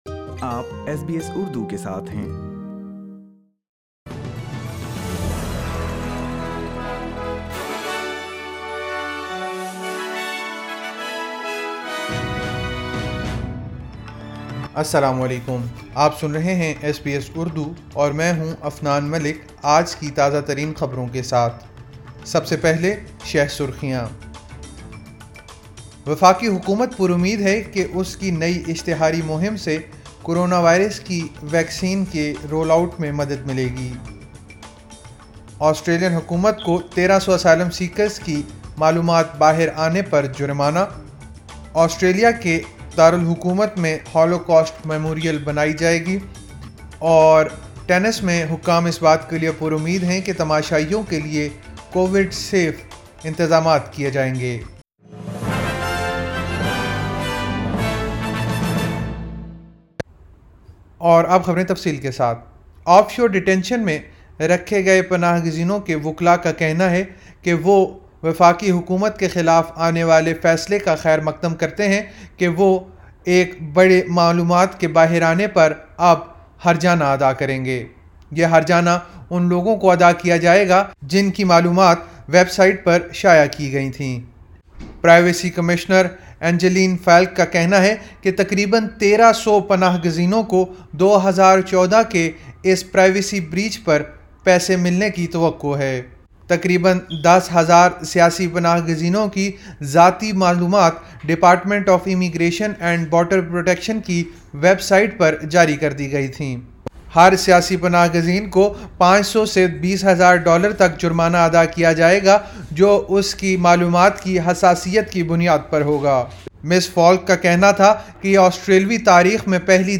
ایس بی ایس اردو خبریں 27 جنوری 2021